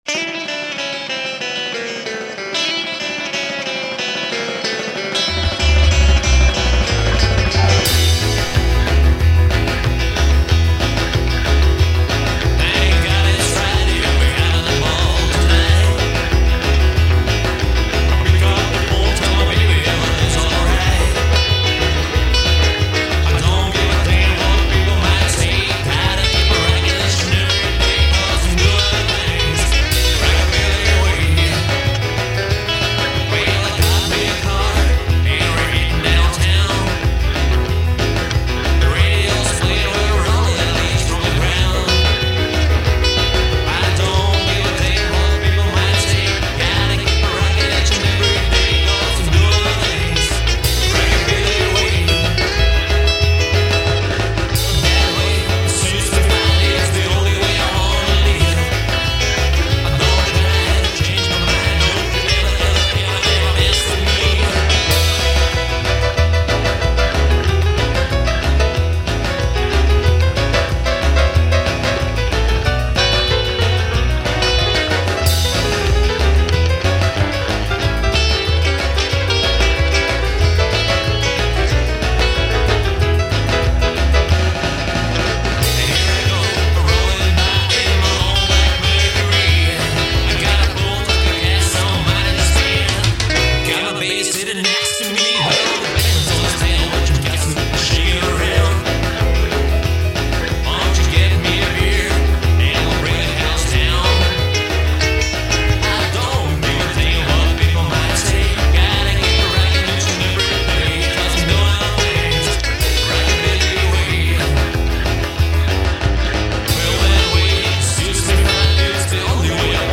Choose a link from the above and enjoy pure rockabilly.